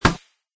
plasticmetal.mp3